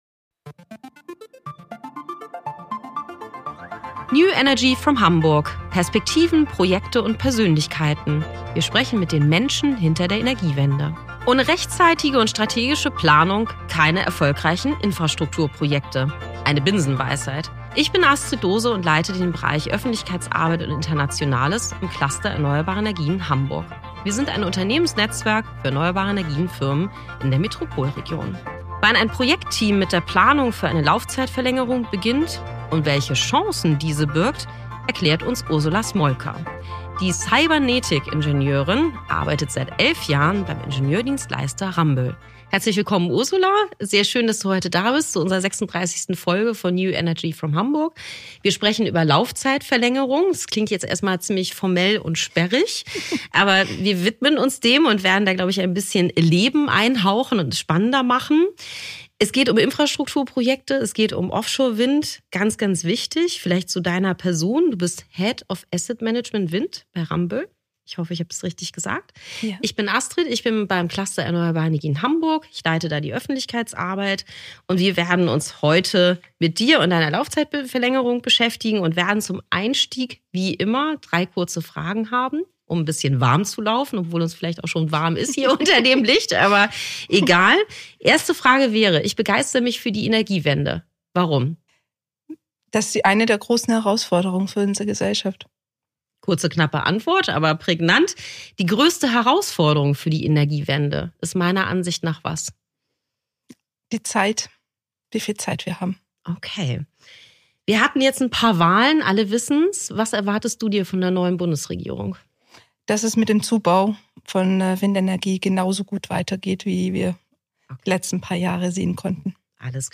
Die Verlängerung von Offshore-Windparks und die Rolle der Digitalisierung - Interview